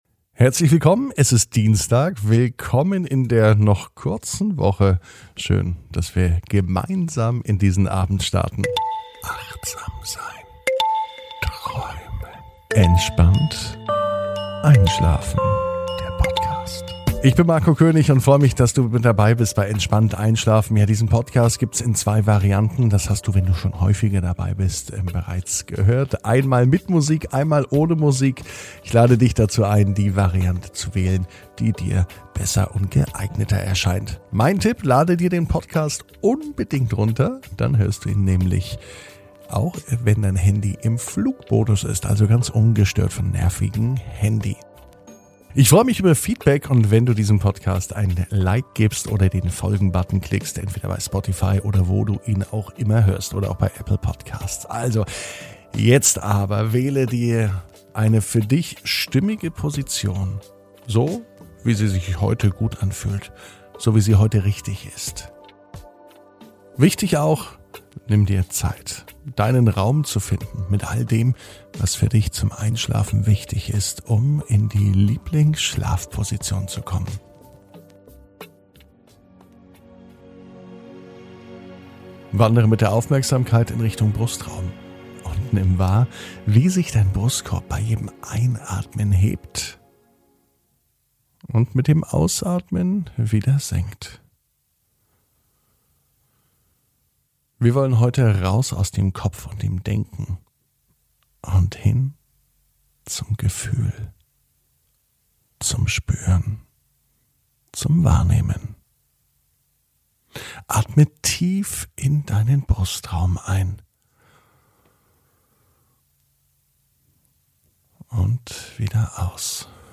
(Ohne Musik) Entspannt einschlafen am Dienstag, 25.05.21 ~ Entspannt einschlafen - Meditation & Achtsamkeit für die Nacht Podcast